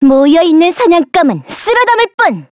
Yanne_L-Vox_Skill2_kr.wav